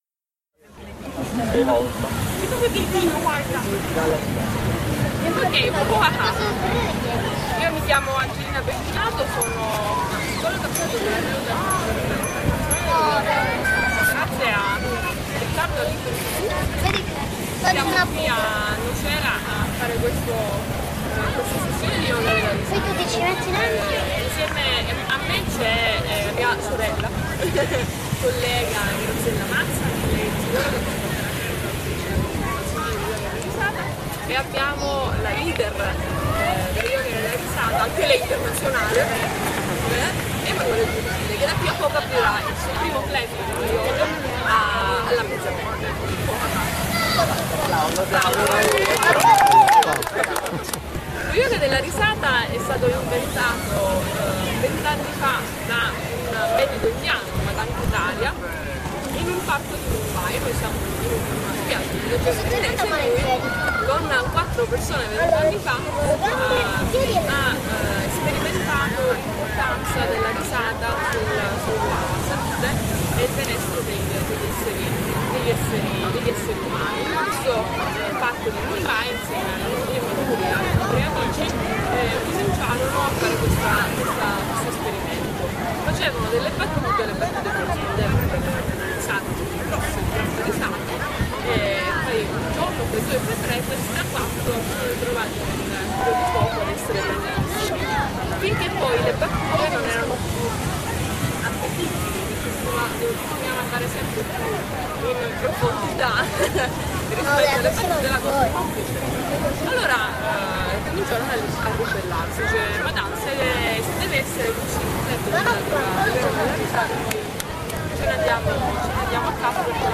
VIDEO | Sessione di Yoga della Risata 1/4
Spiaggia di Nocera Terinese (CZ) 10 luglio 2015.